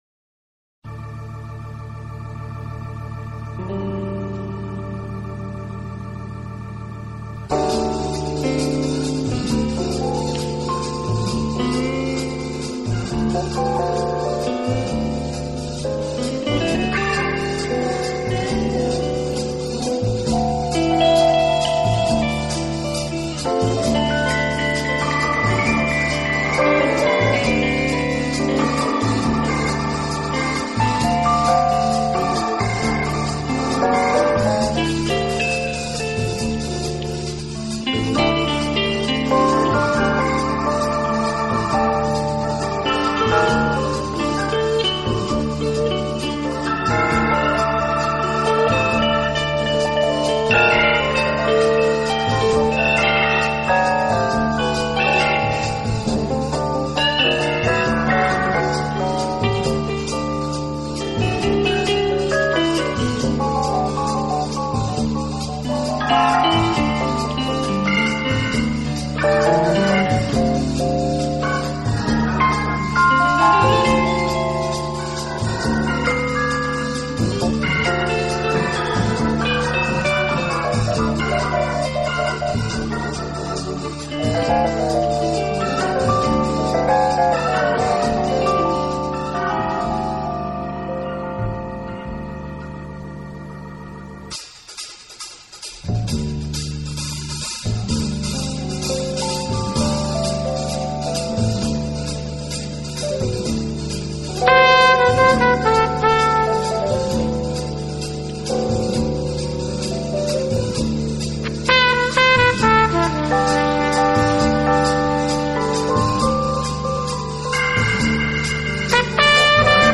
گیتار الکتریک
درام
شاهکار جاز فیوژن